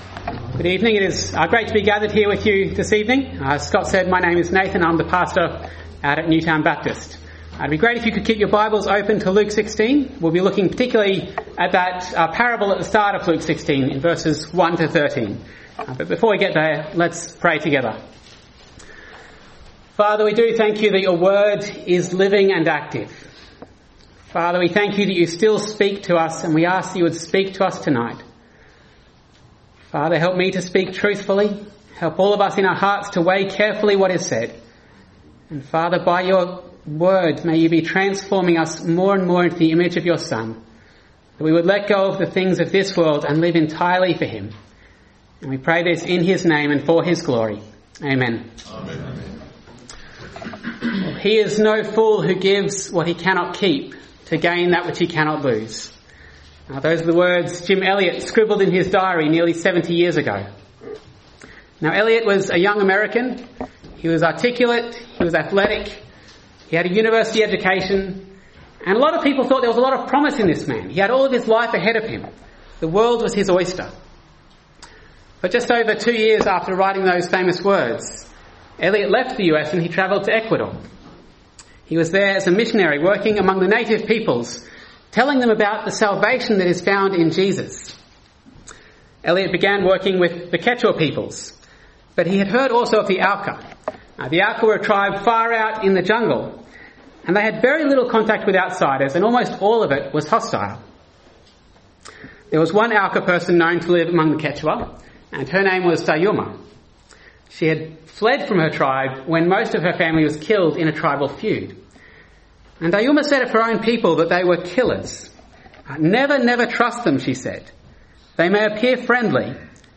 Sermons , Visiting Speakers